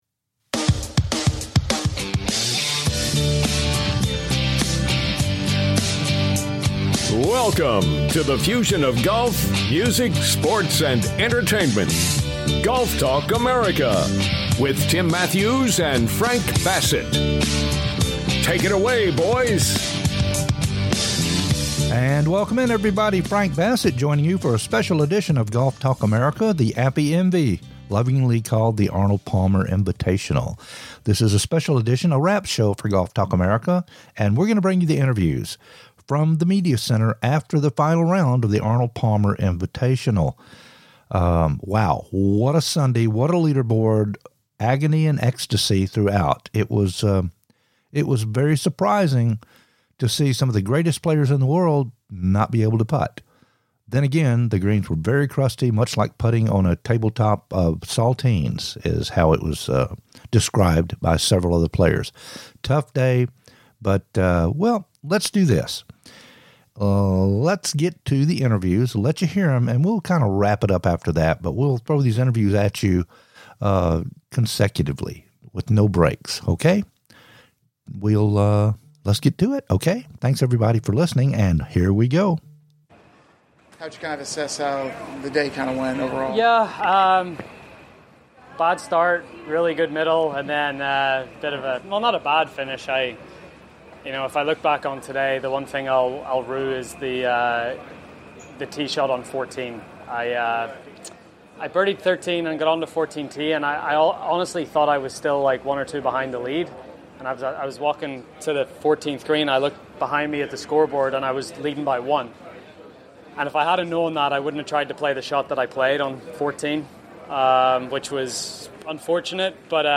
"LIVE" INTERVIEWS FROM THE ARNOLD PALMER INVITATIONAL